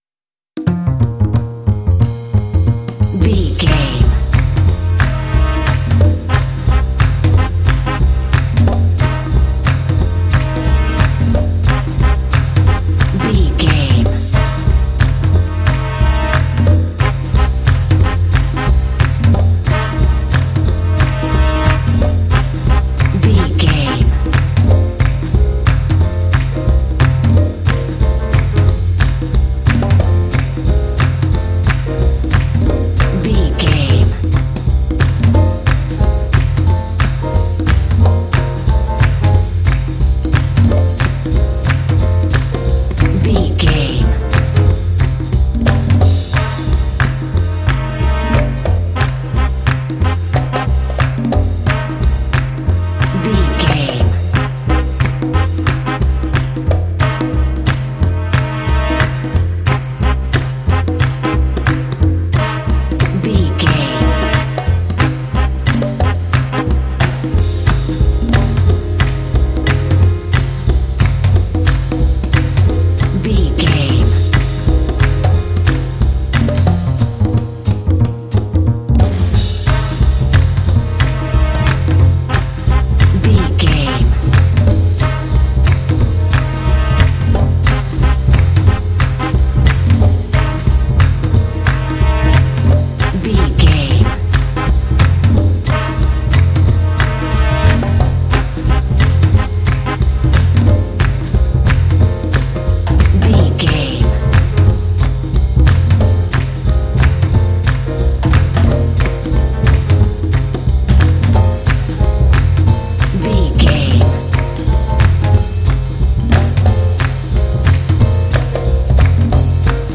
Uplifting
Aeolian/Minor
funky
happy
bouncy
groovy
drums
brass
percussion
bass guitar
saxophone